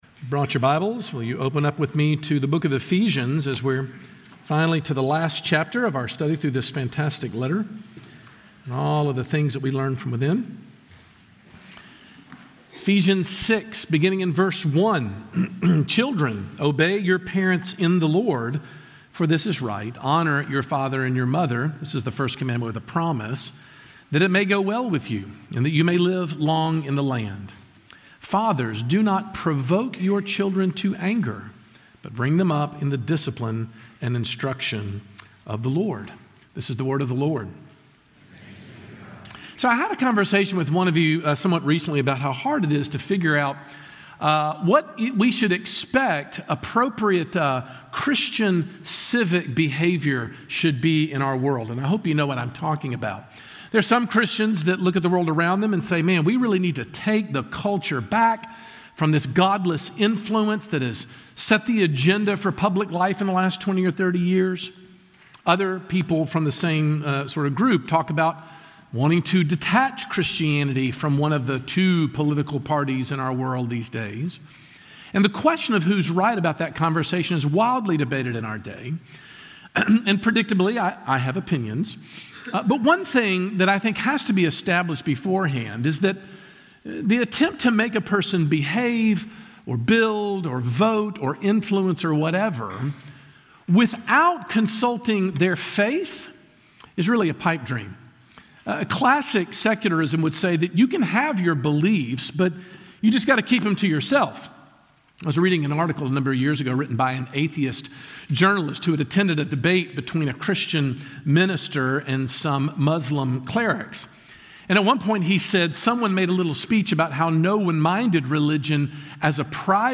Sermon Points: